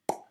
pop3.wav